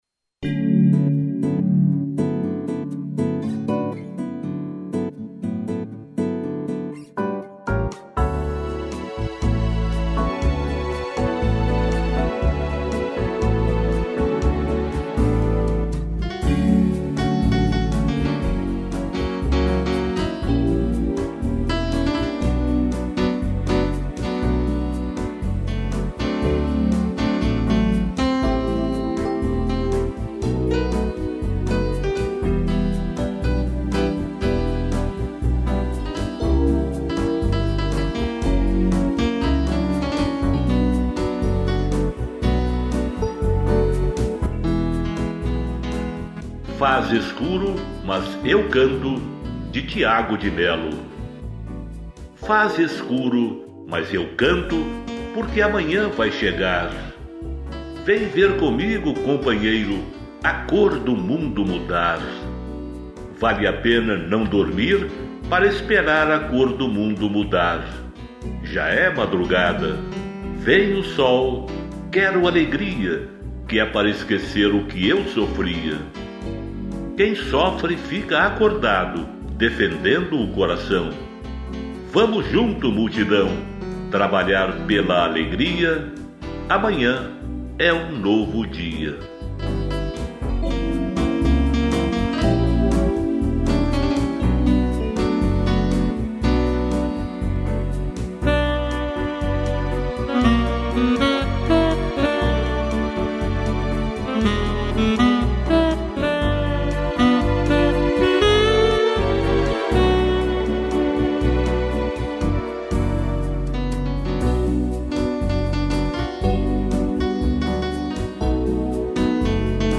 interpretação do texto